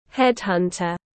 Headhunter /ˈhedˌhʌn.tər/